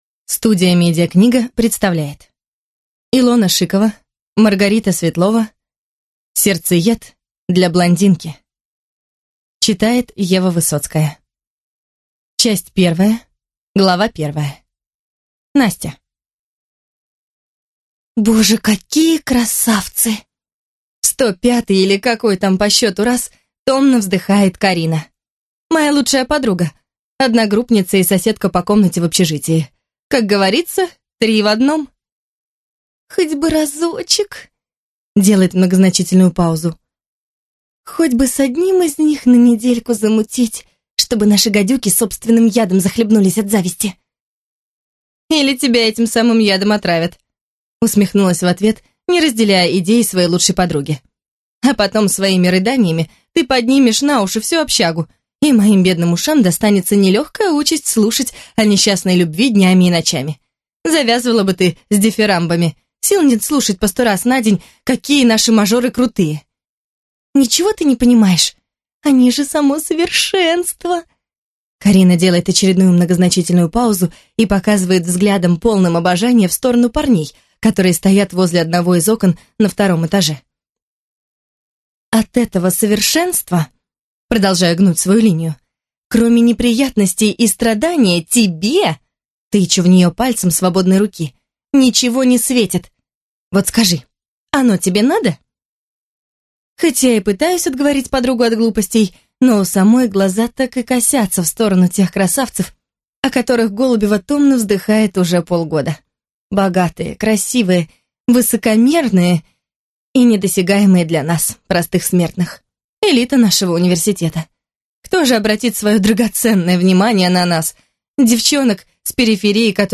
Аудиокнига Сердцеед для блондинки | Библиотека аудиокниг